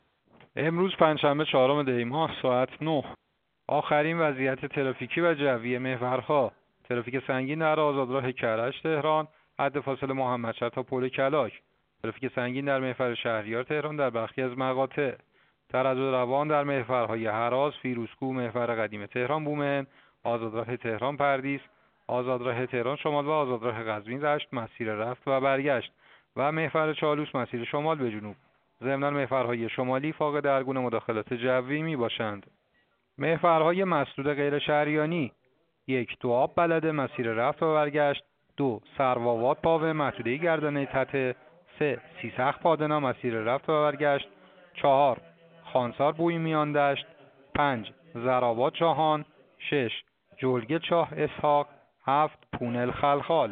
گزارش رادیو اینترنتی از آخرین وضعیت ترافیکی جاده‌ها ساعت ۹ چهارم دی؛